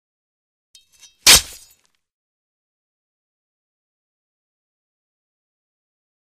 Sword: Stab Through Chain Mail Into Flesh; Sharp, Metallic Scrape Followed By A Quick, Crunchy Slash / Impact. Close Perspective.